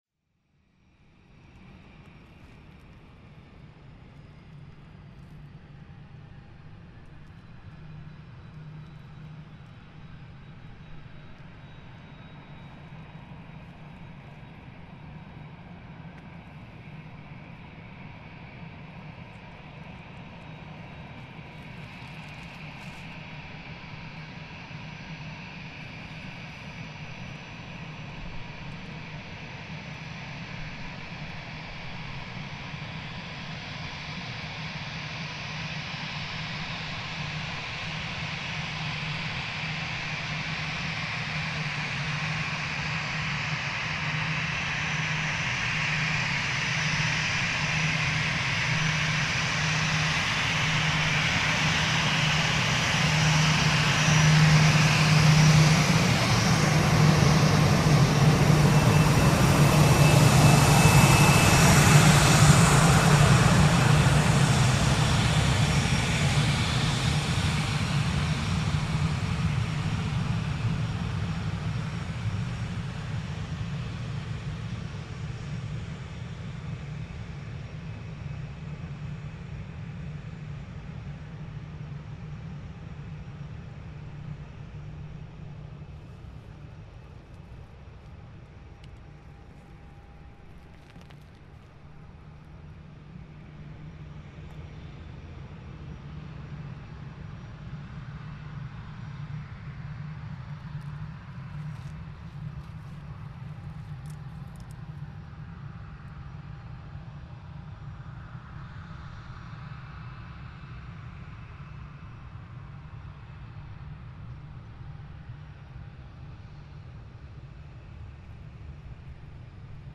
Luton Airport EasyJet taxi and take-off [with bonus private jet] boo